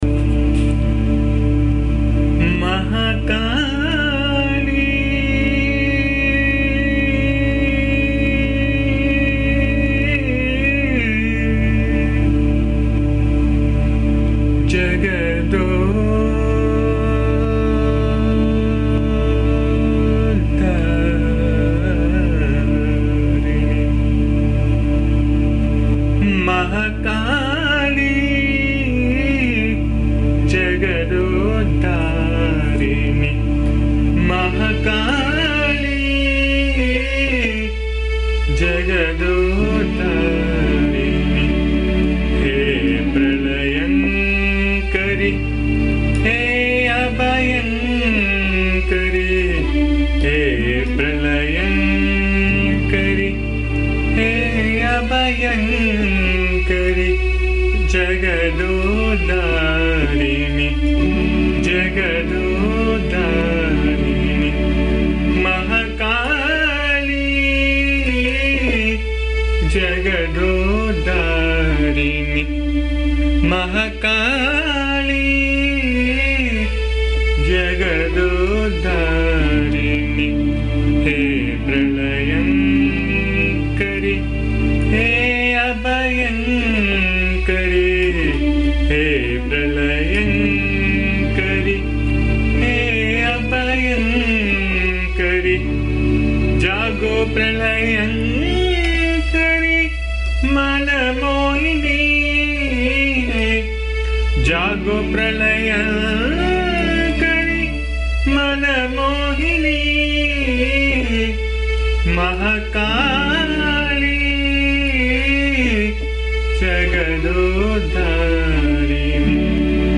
bhajan song